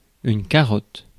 Ääntäminen
France (Paris): IPA: [yn ka.ʁɔt]